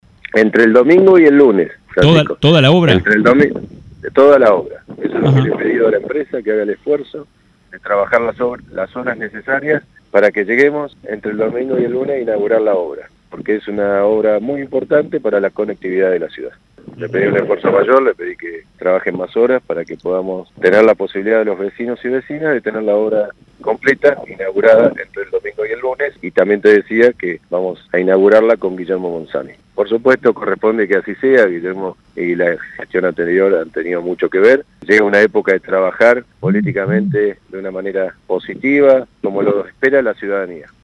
Neuquén.- El intendente Mariano Gaido confirmó en el aire de “Mañanas en Red” por LA RED NEUQUÉN (93.7) que el Novo Vial se inaugurará entre el domingo y el lunes.